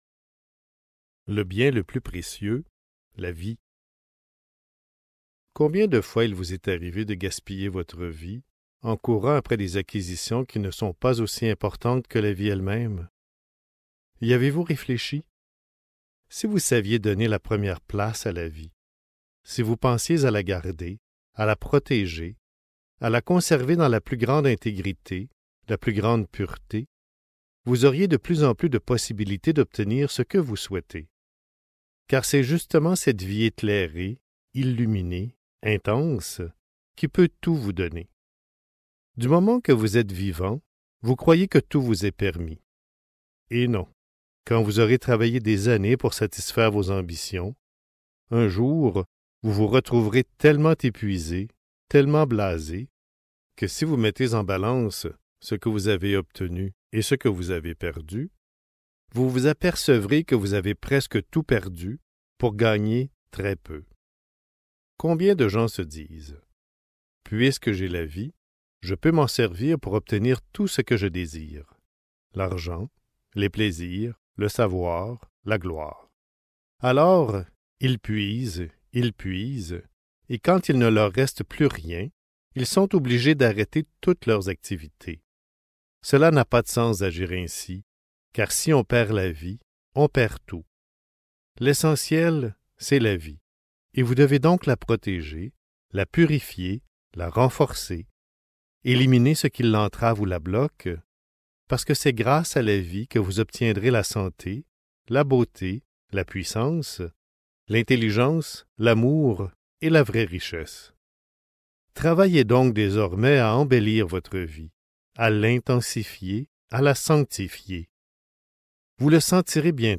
Règles d'or pour la vie quotidienne (Livre audio | CD MP3) | Omraam Mikhaël Aïvanhov